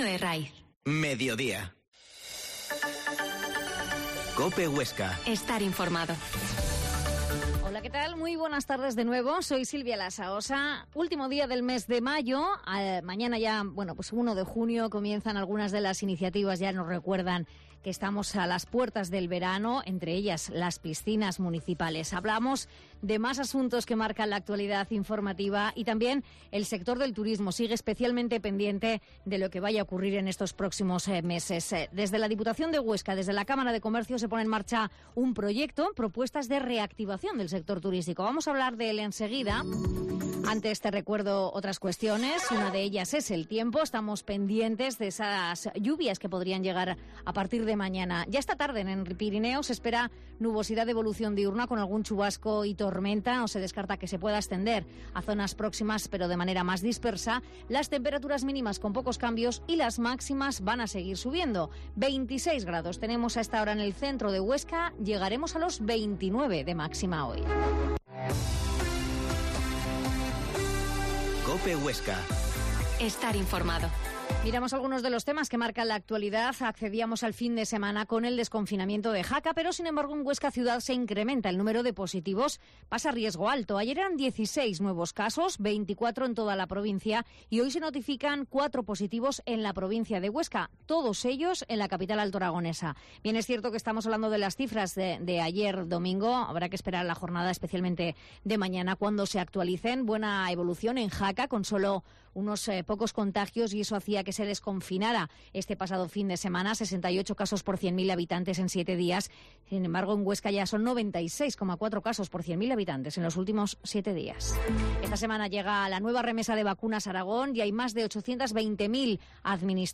Entrevista
La Mañana en COPE Huesca - Informativo local Mediodía en Cope Huesca 13,20h.